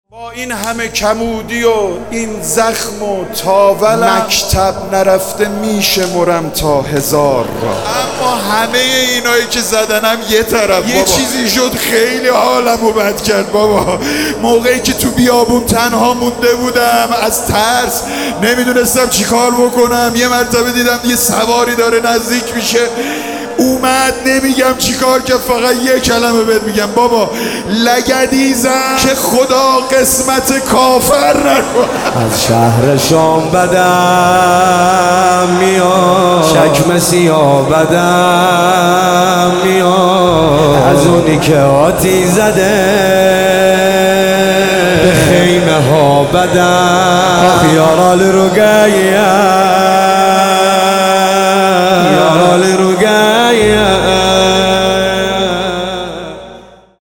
چاووش محرم